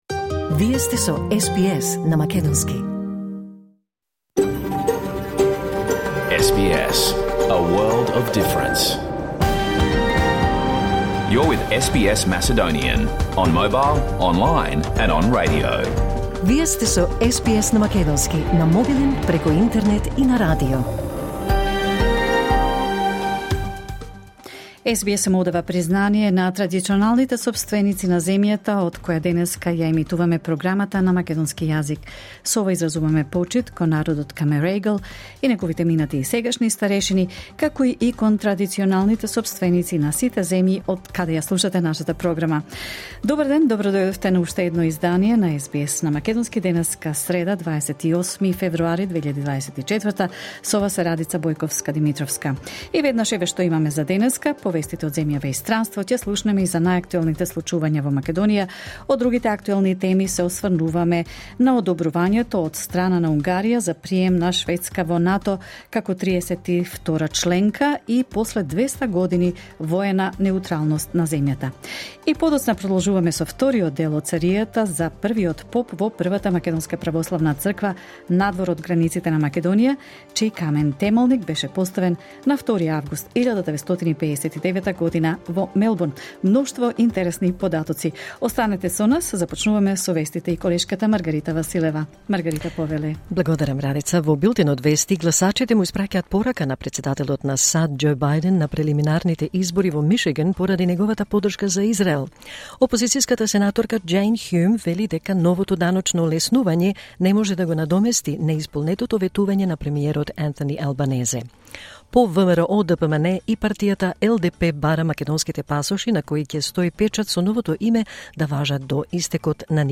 SBS Macedonian Program Live on Air 28 February 2024